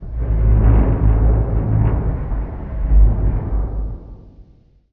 metal_low_creaking_ship_structure_09.wav